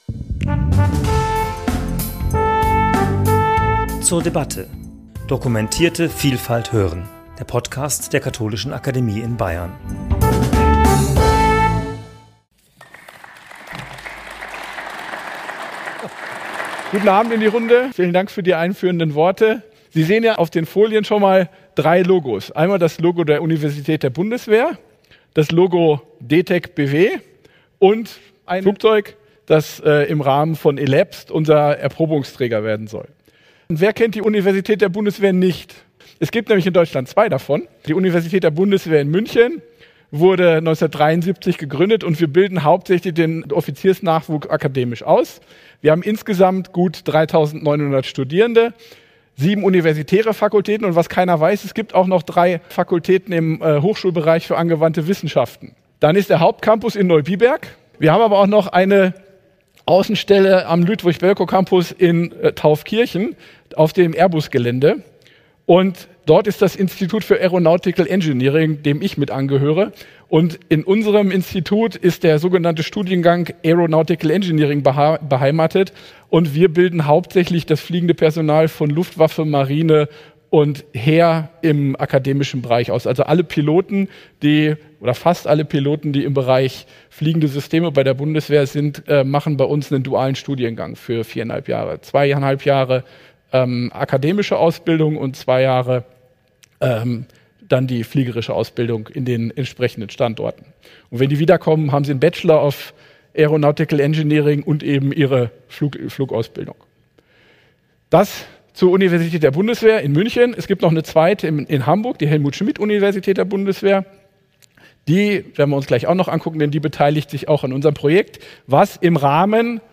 hielt im Deutschen Museum einen Vortrag über neuartige Methoden zur Energiebereitstellung durch Multilevel Batterietechnologie oder Brennstoffzellen und über die Antriebstechnik bis hin zum Propulsor.